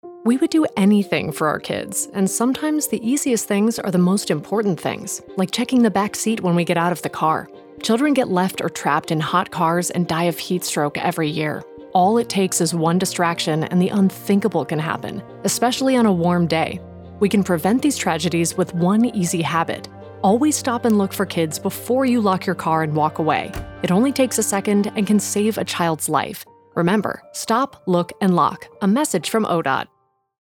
Link to Child Heatstroke Radio PSA in English